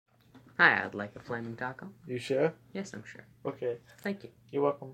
chewing chips variated
描述：Recording of the chewing of chips in different ways with a zoom H6 in a slightly sound proofed room. Slight eq.
标签： man eat chips wav teeth human variated eating mouth munch bite food crunch OWI chew chewing
声道立体声